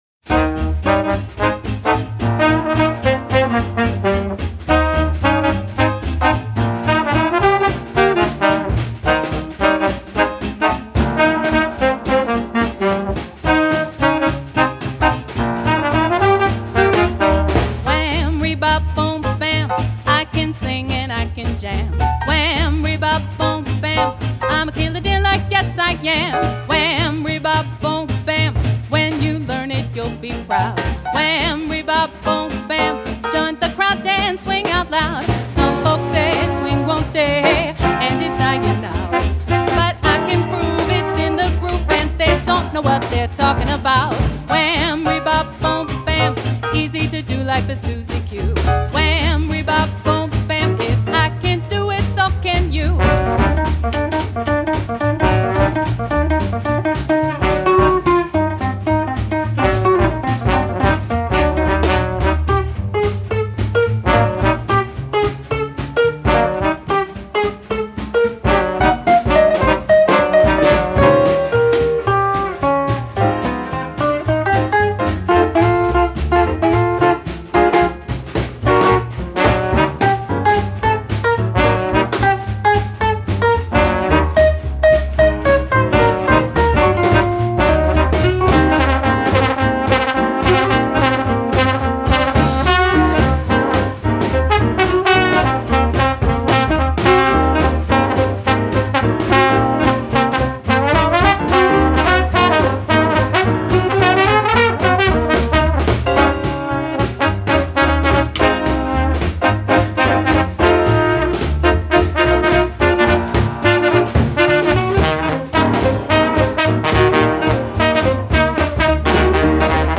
Lindy Hop Music
[Intro-4 x 8 count around 17 seconds-no dancing but moving]